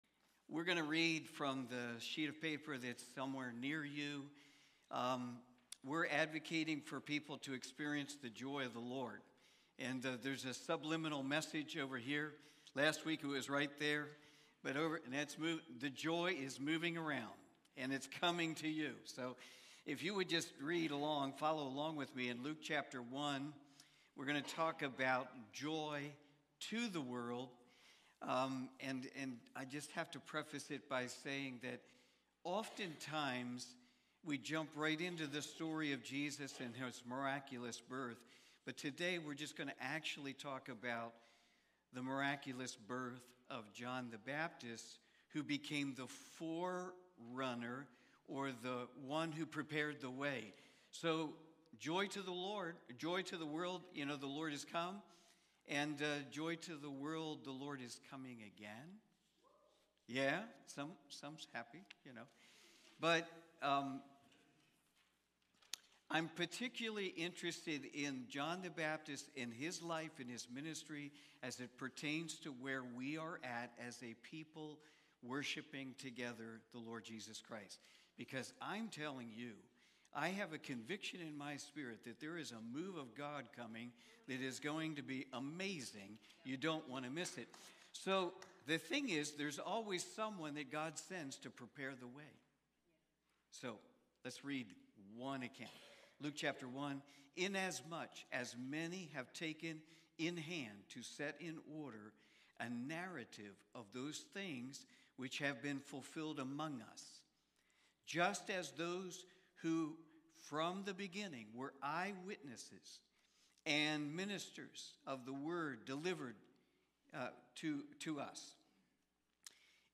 Malachi Watch Listen Save Cornerstone Fellowship Sunday morning service, livestreamed from Wormleysburg, PA.